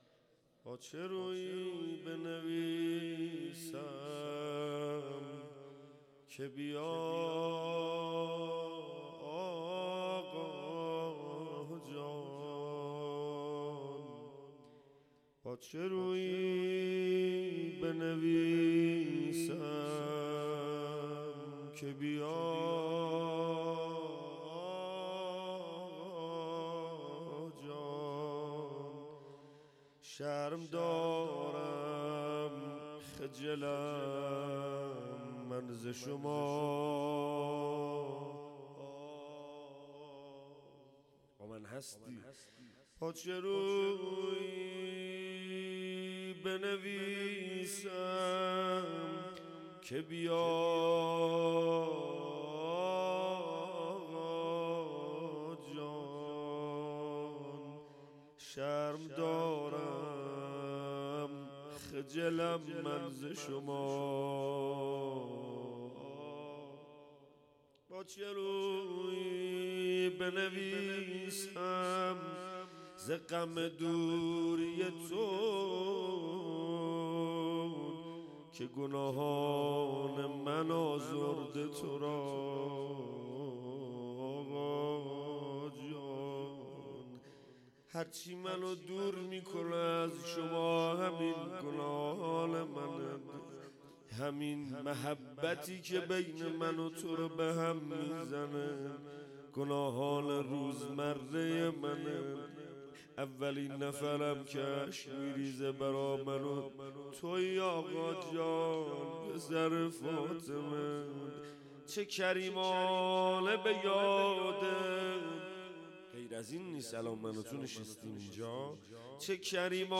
21 اُمین محفل بهشتی، 21 تیر 1396، پاسداران، بلوار شهیدان شاه حسینی، مسجد حضرت صاحب الزمان(عج)